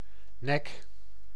Description male voice pronunciation for "nek" .